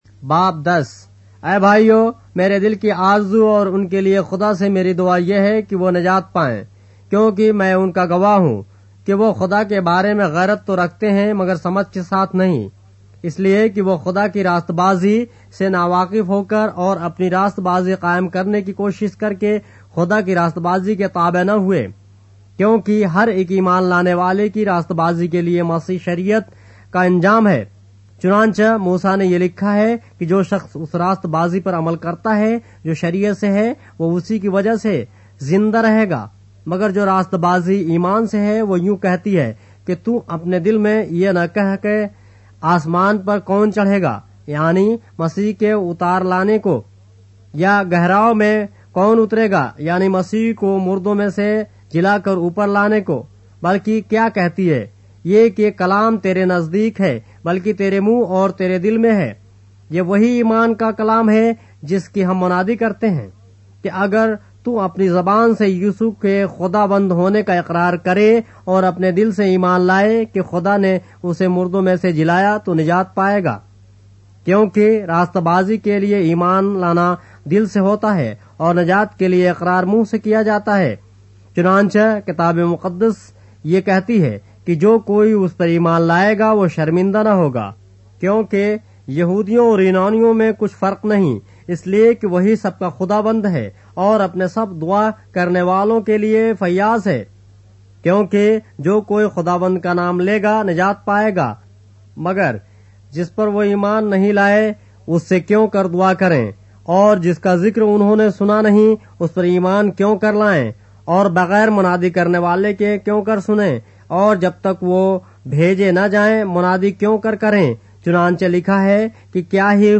اردو بائبل کے باب - آڈیو روایت کے ساتھ - Romans, chapter 10 of the Holy Bible in Urdu